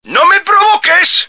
flak_m/sounds/male2/est/M2OnFire.ogg at ac4c53b3efc011c6eda803d9c1f26cd622afffce